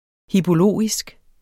Udtale [ hiboˈloˀisg ]